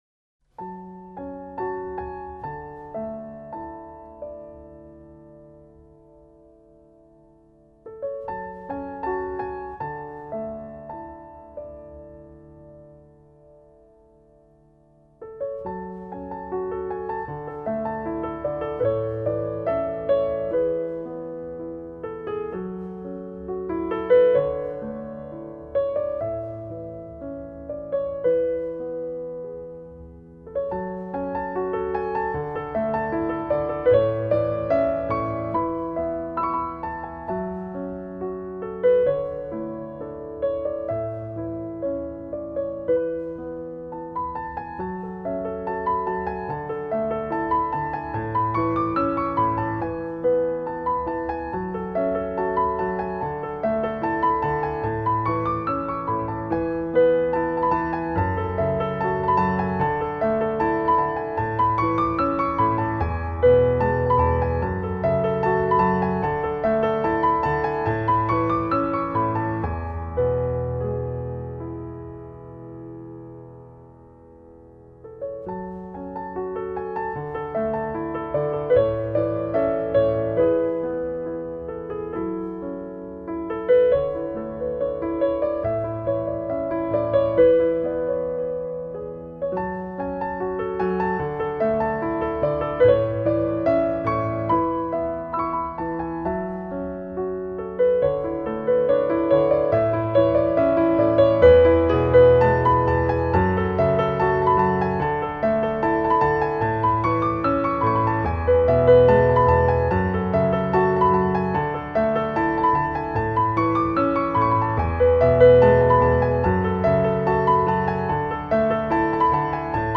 随音符时而静谧时而浪漫，时而婉约时而飞扬，思绪仿佛被悠扬的旋律带入芬芳气息的浓密林荫下，感受那无尽的优美音符细密洗礼。